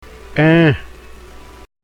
Contrast between palatal and non-palatal consonant sounds
The Pronunciation Practice